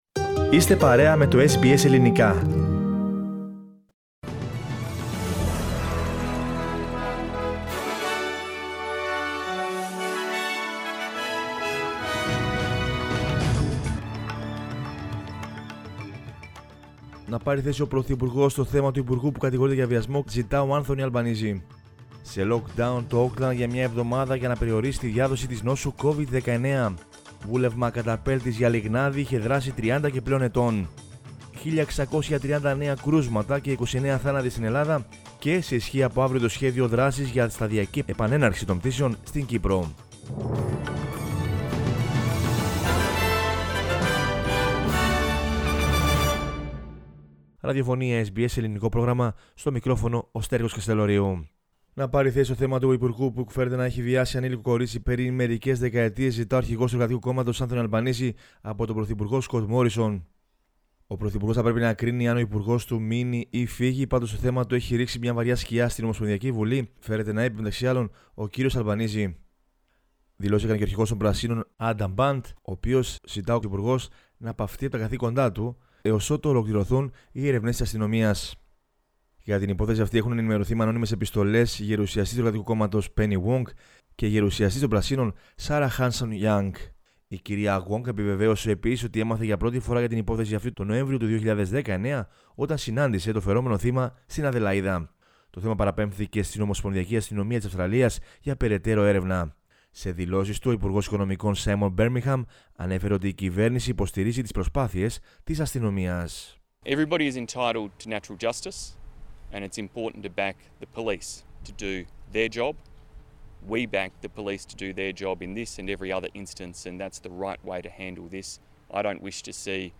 News in Greek from Australia, Greece, Cyprus and the world is the news bulletin of Sunday 28 February 2021.